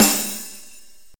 soft-hitwhistle1.wav